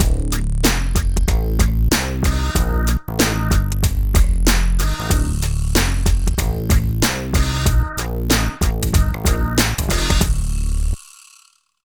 87 LOOP   -R.wav